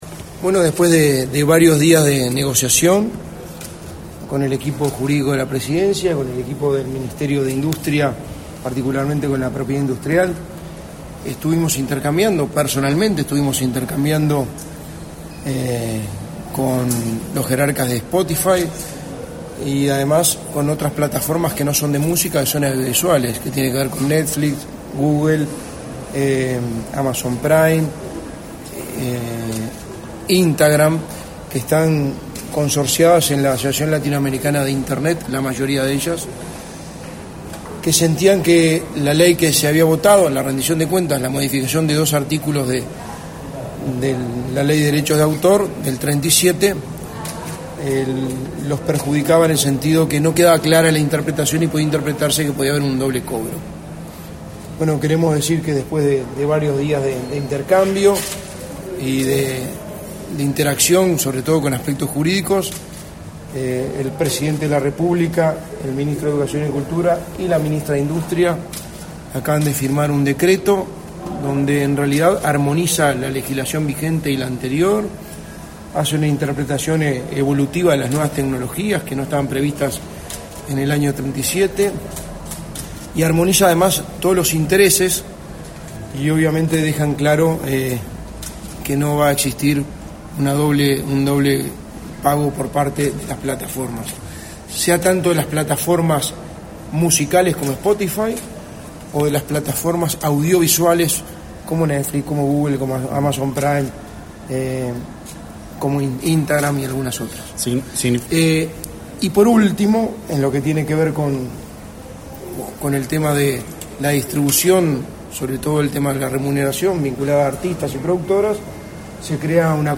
Palabras del secretario de la Presidencia, Álvaro Delgado
El jerarca disertó en el evento.